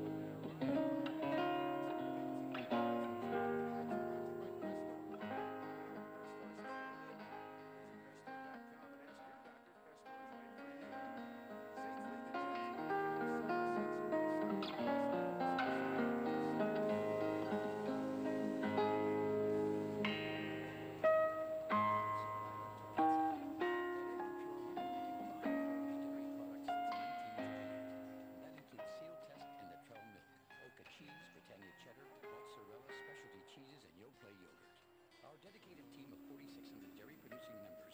music from EWTN
Below are a set of sound recordings from this receiver.
The recordings show how the volume can change (no AGC here!) and that the selectivity is not too good.
Guitar music Ogg Vorbis format.
guitar.ogg